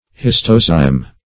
Search Result for " histozyme" : The Collaborative International Dictionary of English v.0.48: Histozyme \His"to*zyme\, n. [Gr.